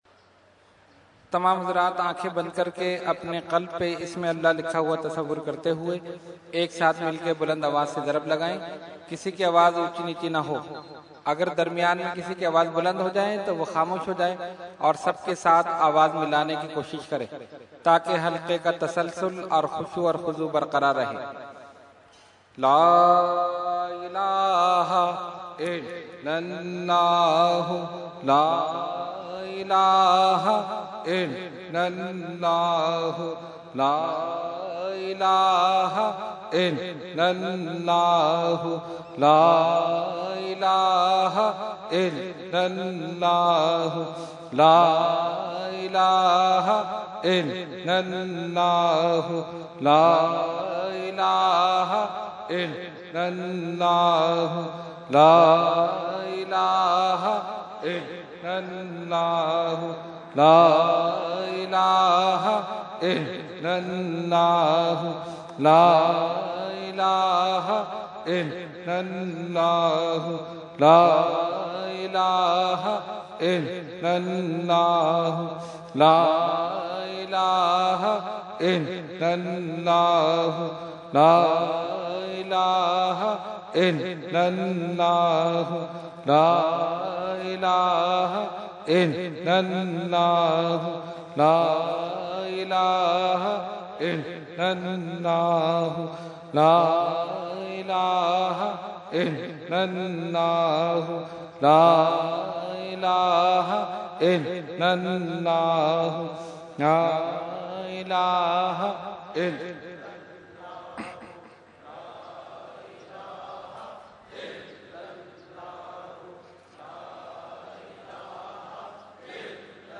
Zikar – Urs e Makhdoom e Samnani 2013 Day3 – Dargah Alia Ashrafia Karachi Pakistan
Category : Zikar | Language : ArabicEvent : Urs e Makhdoom e Samnani 2013